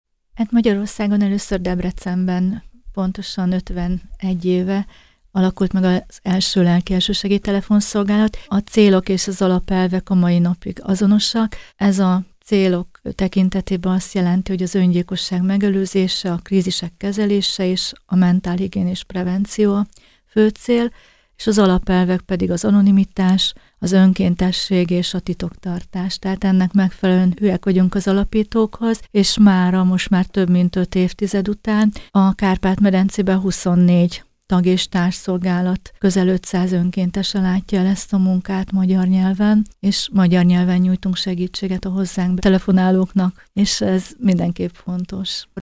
lelki_segely_riport_hirekbe.mp3